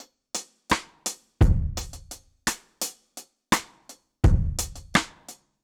Index of /musicradar/dub-drums-samples/85bpm
Db_DrumsB_Dry_85-01.wav